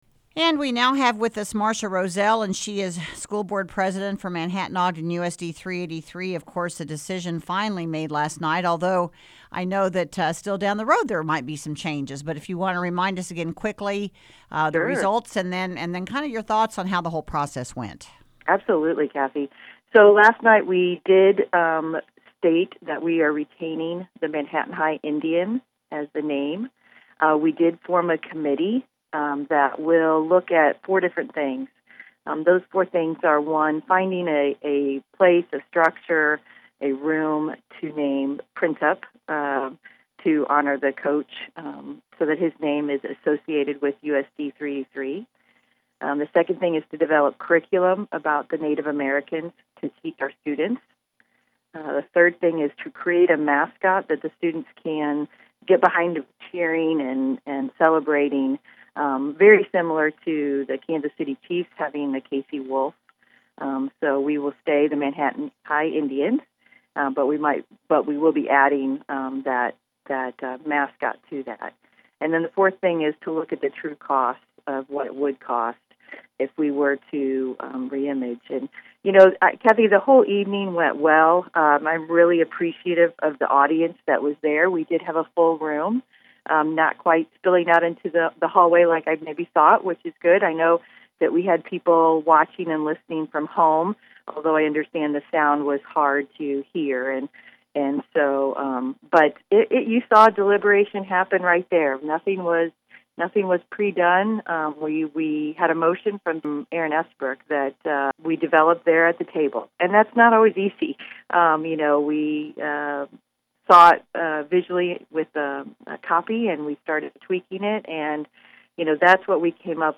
Thursday morning interview with Board President Marcia Rozell follows: